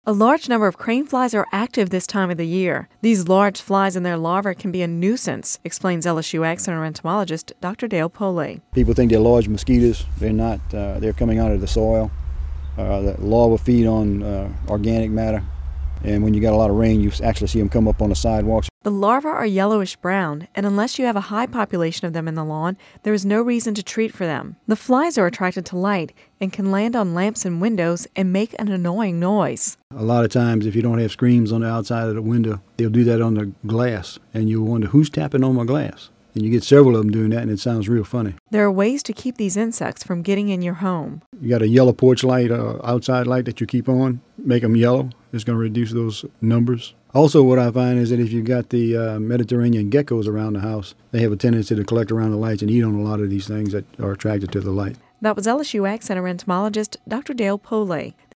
(Radio News 03/22/10) A large number of crane flies are active this time of the year.